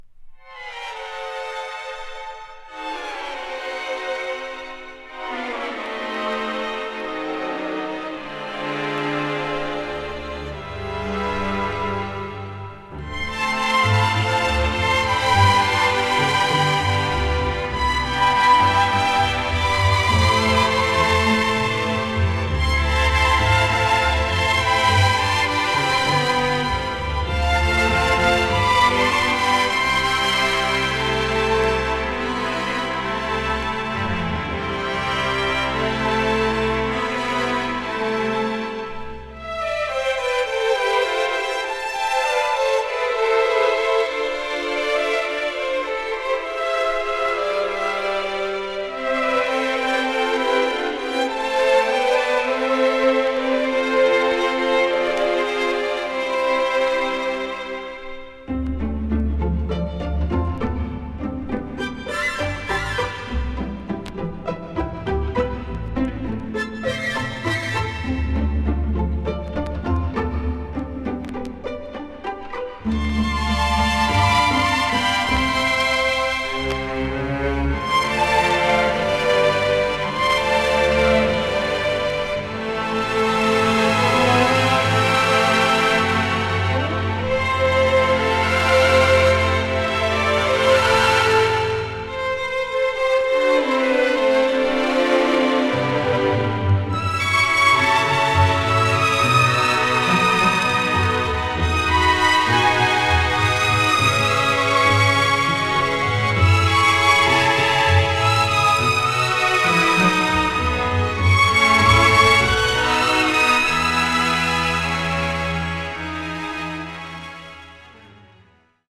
ストリングスが前面に出たイージー・リスニング要素たっぷりのインスト・アルバム。